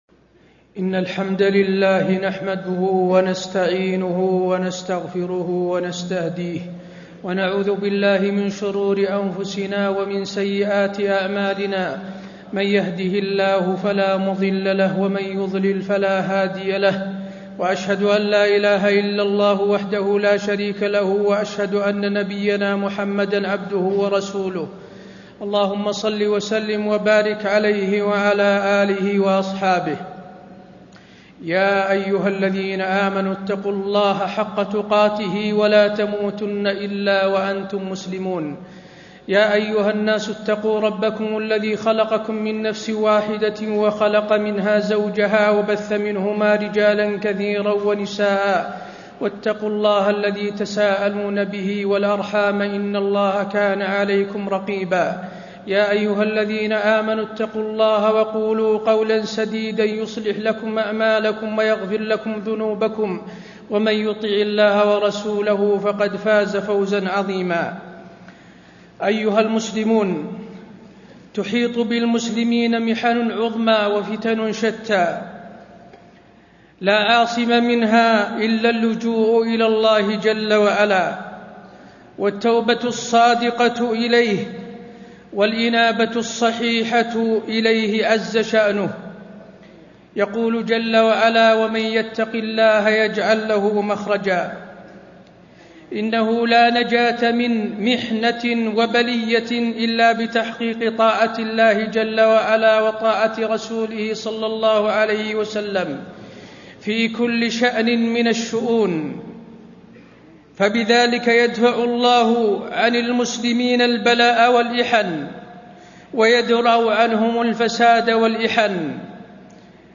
تاريخ النشر ١٧ ذو القعدة ١٤٣٥ هـ المكان: المسجد النبوي الشيخ: فضيلة الشيخ د. حسين بن عبدالعزيز آل الشيخ فضيلة الشيخ د. حسين بن عبدالعزيز آل الشيخ التحذير من خطورة التكفير